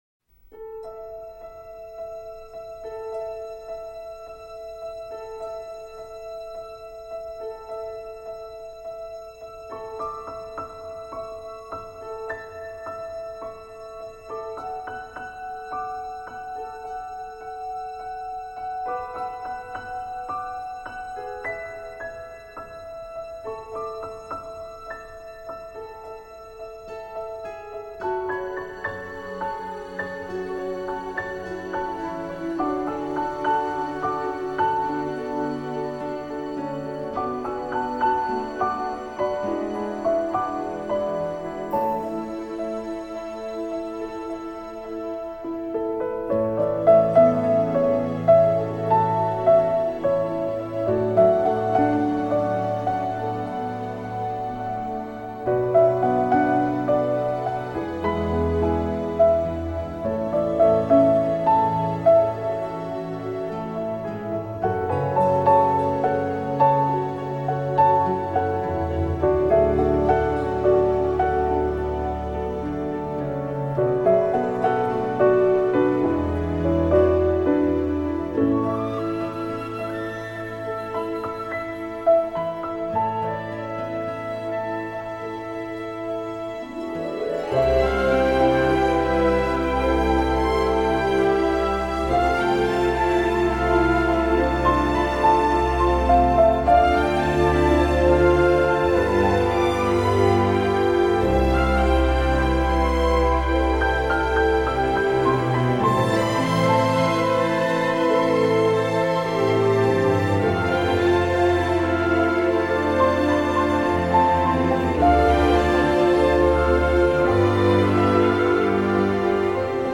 轻音乐
该BGM音质清晰、流畅，源文件无声音水印干扰，